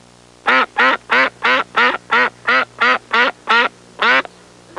Excited Duck Sound Effect
Download a high-quality excited duck sound effect.
excited-duck.mp3